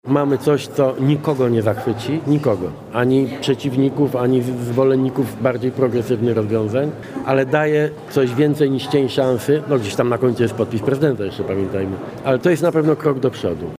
– „To dopiero mały krok w dobrym kierunku” – zaznacza jednak szef rządu, komentując projekt ustawy: